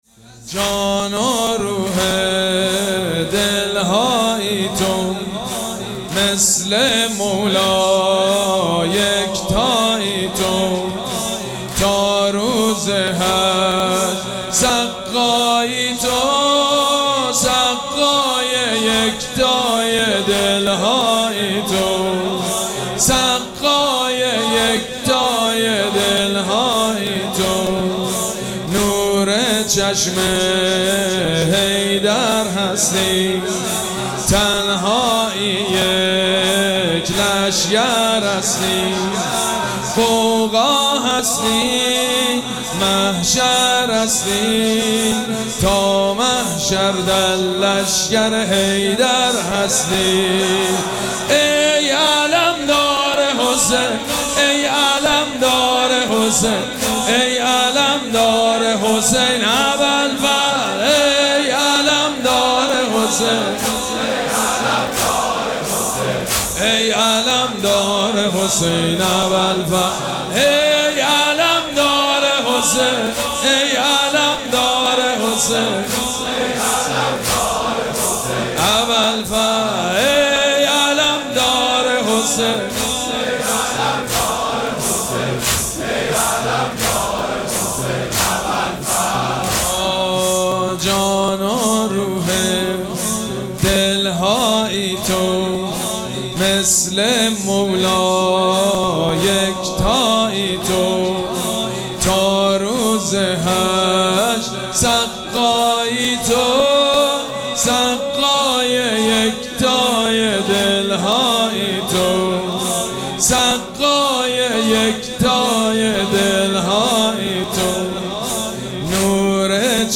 مراسم عزاداری شب نهم محرم الحرام ۱۴۴۷
مداح
حاج سید مجید بنی فاطمه